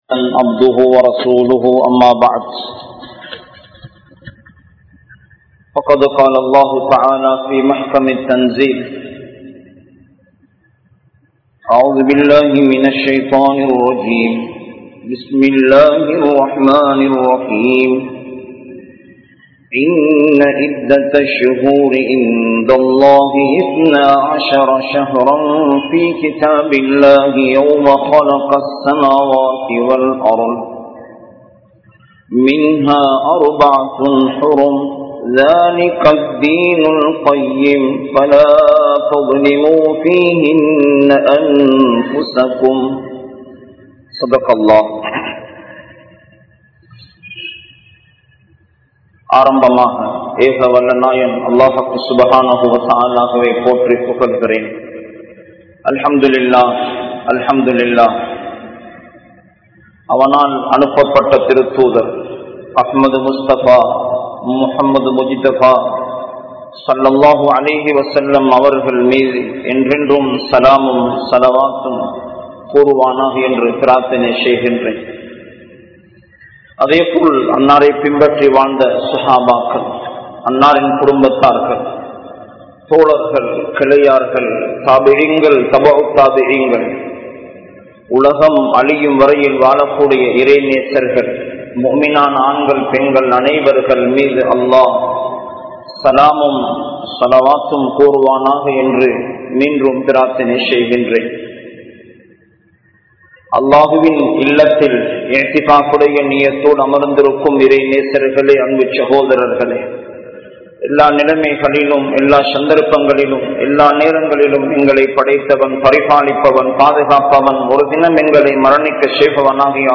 Allah`vin Sakthi (அல்லாஹ்வின் சக்தி) | Audio Bayans | All Ceylon Muslim Youth Community | Addalaichenai